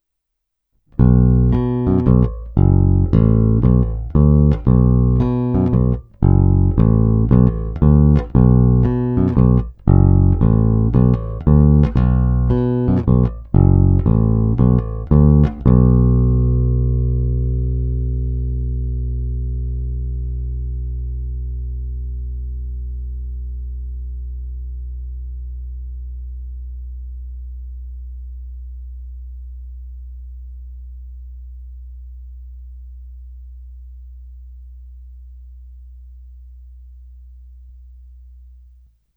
Xotic je spíš moderně pevný.
Není-li uvedeno jinak, následující nahrávky jsou provedeny rovnou do zvukové karty, jen normalizovány, jinak ponechány bez úprav. Tónová clona vždy plně otevřená, stejně tak korekce ponechány na středu.
Hra nad snímačem